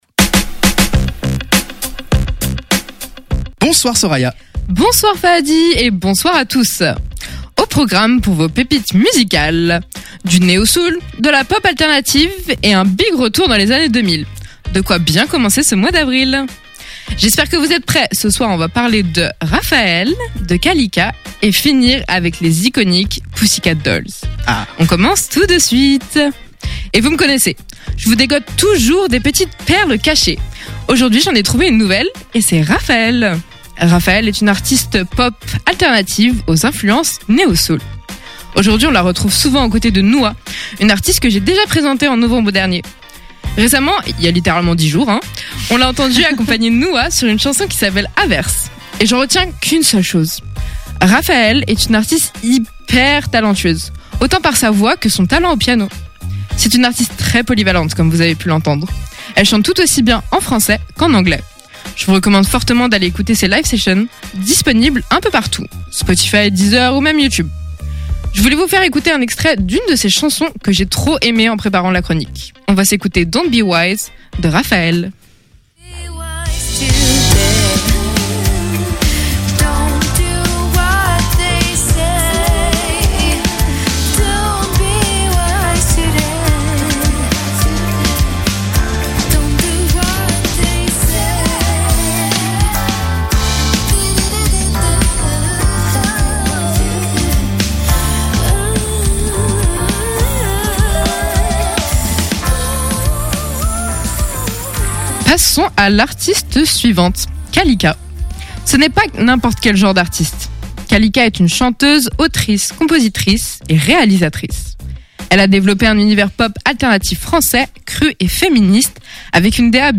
Au programme pour vos pépites musicales : du neo-soul, de la pop alternative et un big